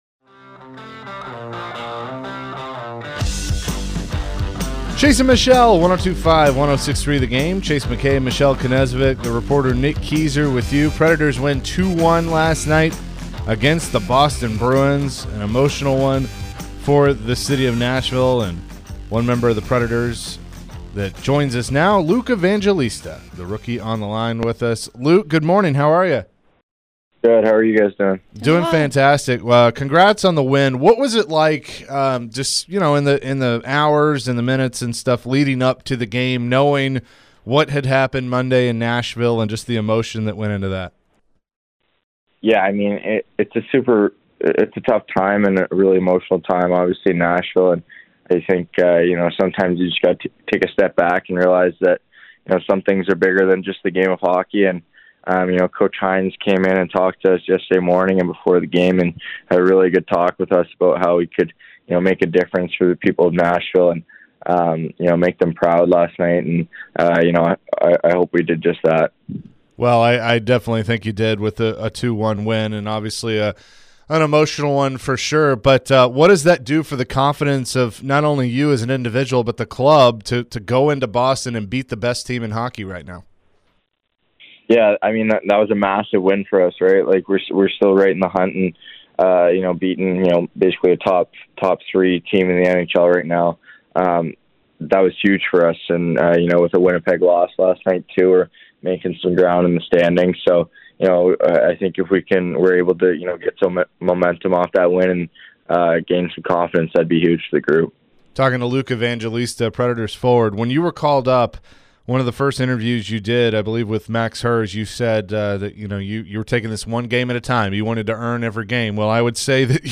Luke Evangelista Interview (3-29-23)
Nashville Predators Rookie Luke Evangelista joined the show after a 2-1 win Boston. The Preds handed the Bruins their 4th loss in regulation at home this season.